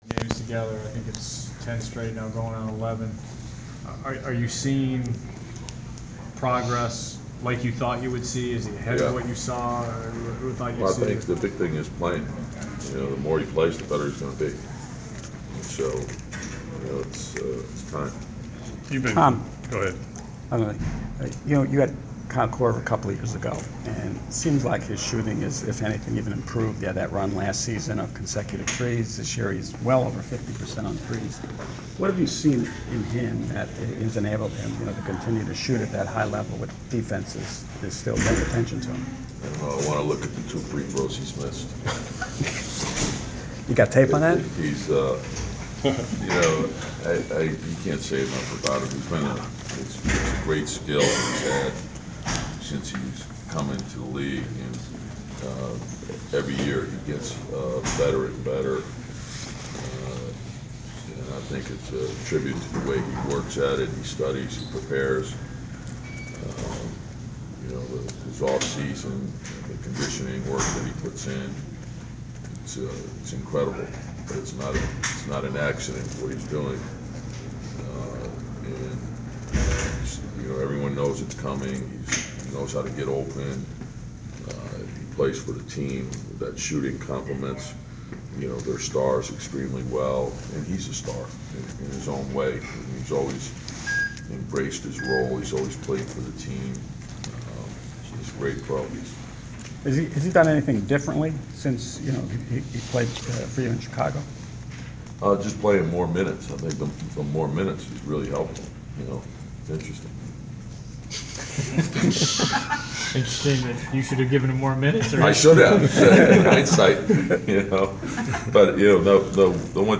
Inside the Inquirer: Pregame presser with Chicago Bulls’ head coach Tom Thibodeau (12/15/14)
We attended the pregame presser of Chicago Bulls’ head coach Tom Thibodeau before his team’s road contest at the Atlanta Hawks on Dec. 15. Topics included the health of Joakim Noah, defending Kyle Korver, the depth of the Bulls’ roster and dealing with injuries.